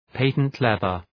Προφορά
{,pætənt’leðər}